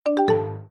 Play, download and share duuuut original sound button!!!!
msn-messenger-message-sound.mp3